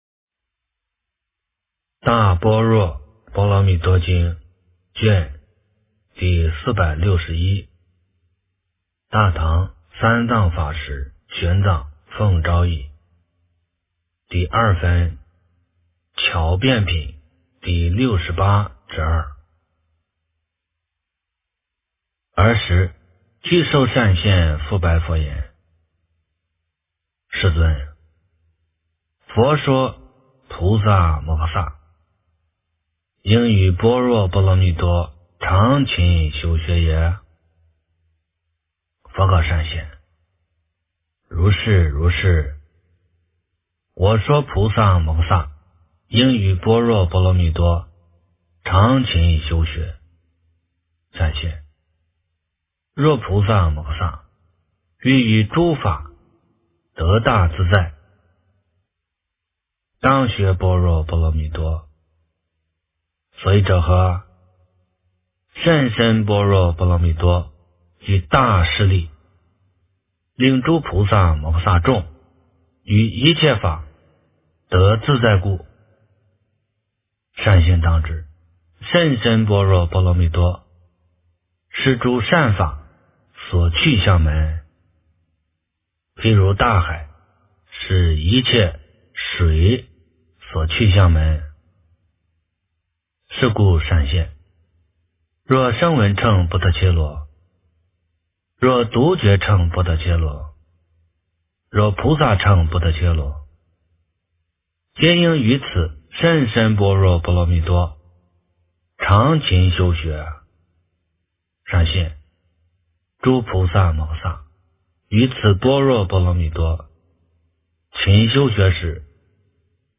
大般若波罗蜜多经第461卷 - 诵经 - 云佛论坛